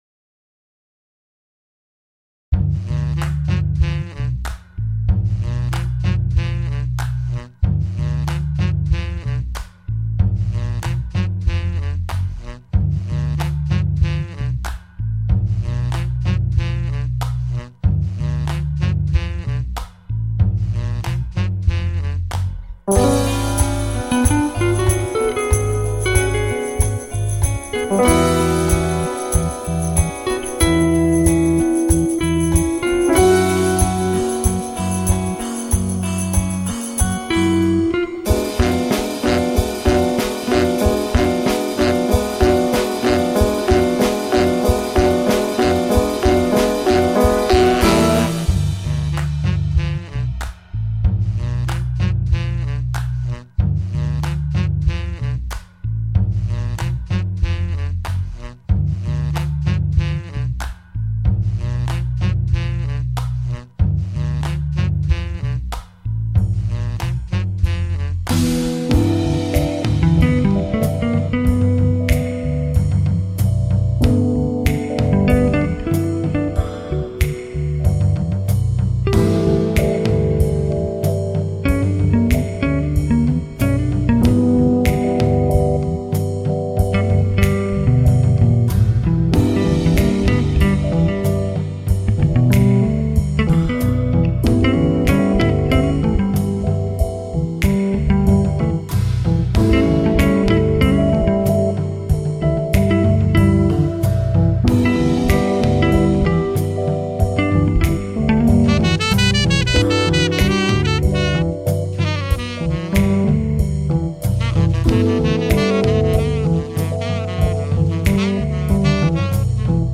Jazz with a story...
Tagged as: Jazz, Alt Rock